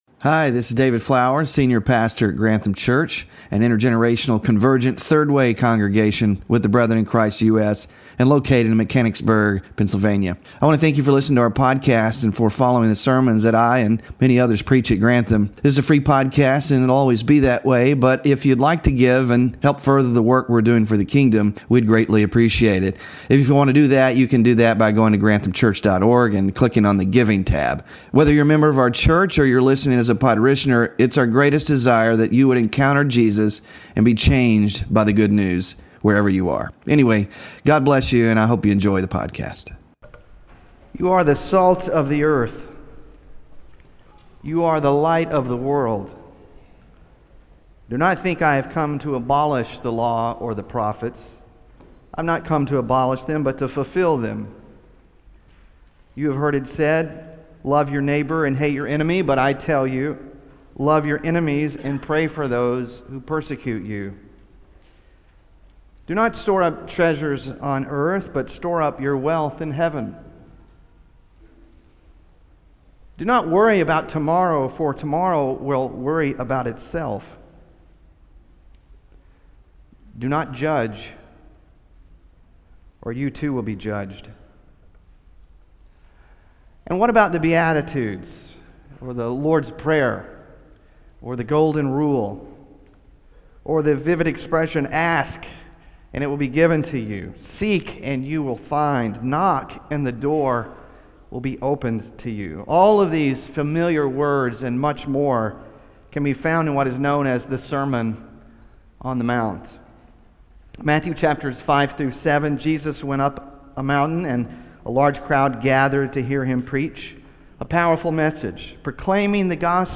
Scripture Reading: Matthew 4:17; 7:13-14; 10:38; 16:24-25